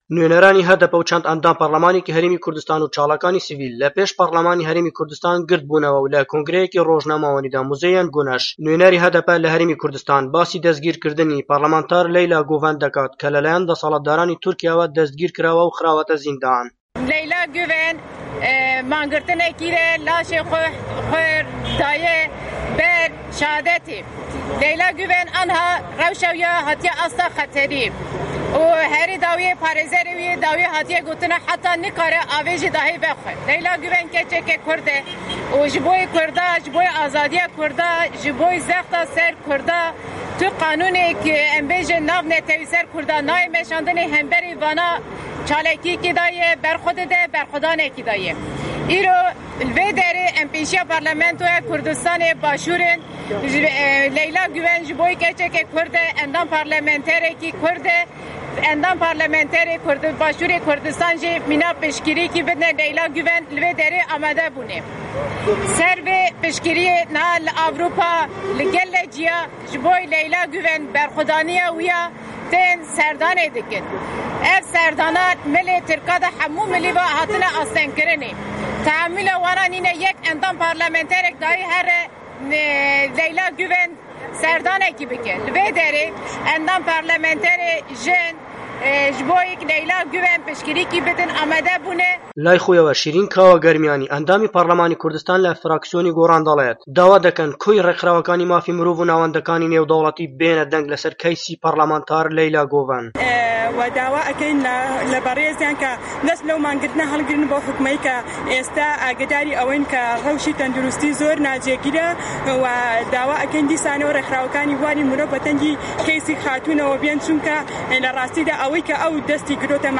نوێنەرانی هەدەپە و چەند ئەندام پەرلەمانێكی هەرێمی كوردستان و چالاكانی سڤیل لە پێش پەرلەمانی هەرێمی كوردستان گردبوونەوە.
ڕاپـۆرتی